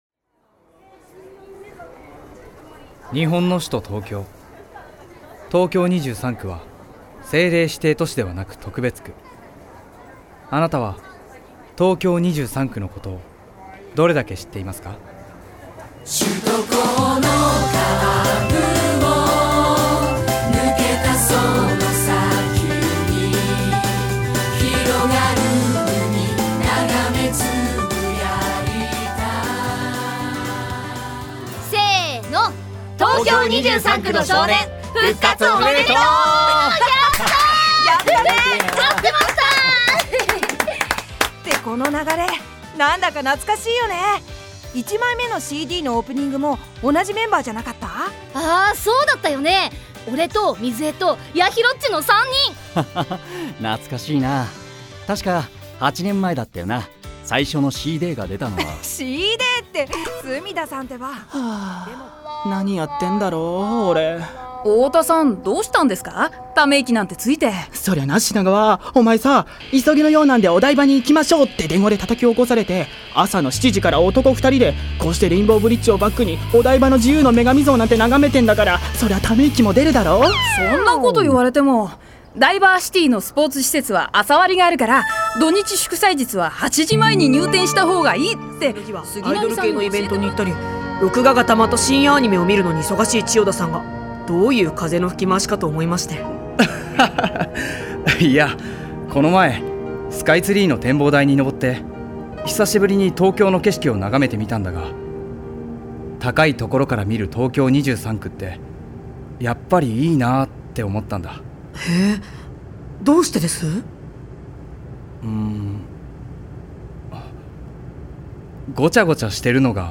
擬人化ボイスドラマ『東京23区の少年』は東京23区のうんちくあり、笑いあり、たまにはちょっぴりシリアスありのギャグボイスドラマCDです。
内　容　　：　擬人化ボイスドラマ、歌2曲
クロスフェード　5/2公開！OP曲、ED曲23区全員出演